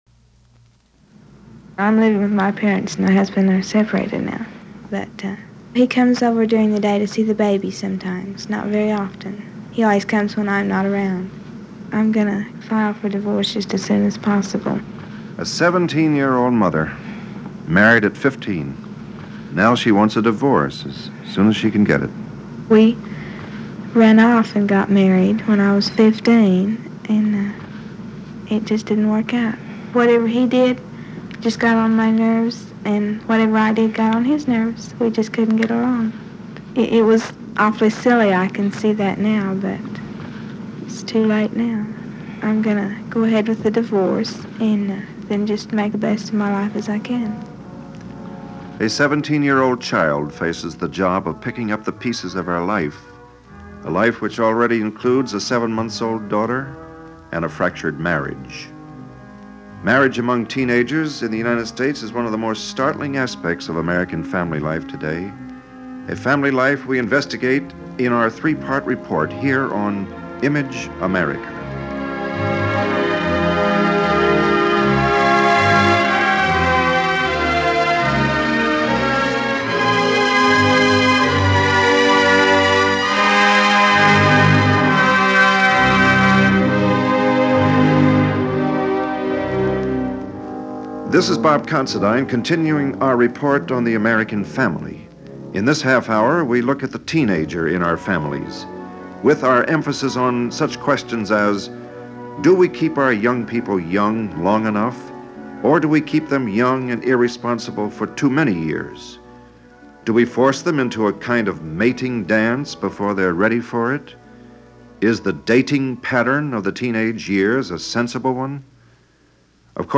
This documentary, part of the weekly radio series Image America, focuses on Teenagers in 1960.
Truths to tell – no you weren’t. So here is that half-hour segment of Image America: Teenagers – 1960, as narrated by Bob Considine and broadcast on April 6, 1960.